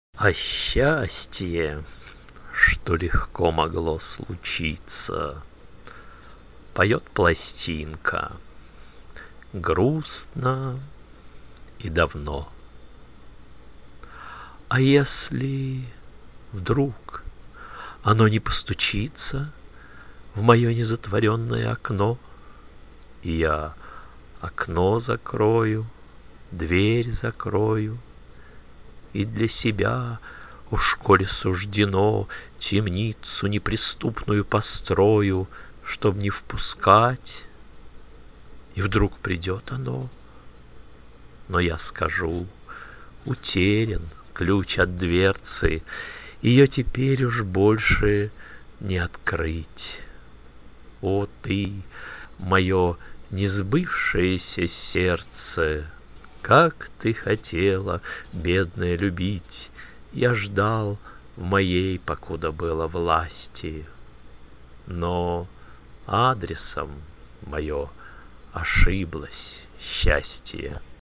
Я загружусь и прочту вам.